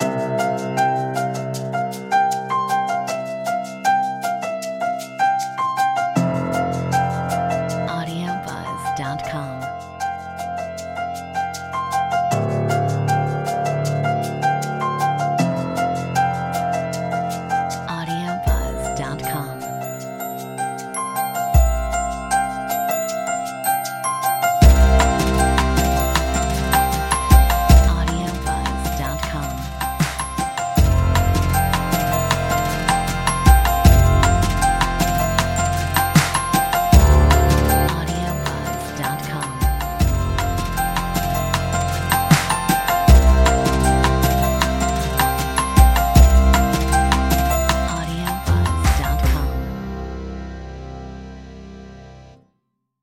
Metronome 150
Upbeat piano arpeggio riff with bells and rich pads.